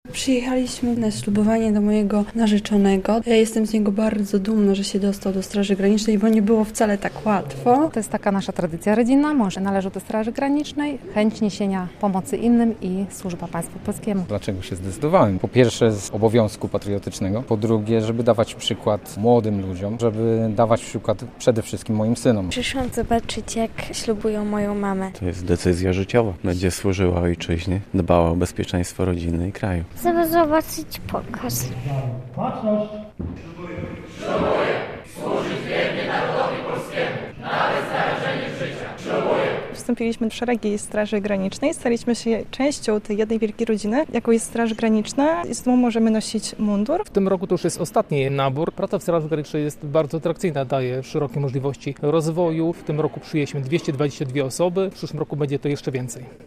Ponad 40 osób wstąpiło w piątek (13.12) w szeregi Podlaskiego Oddziału Straży Granicznej. Na uroczyste ślubowanie do Białegostoku przyjechały ich rodziny i przyjaciele.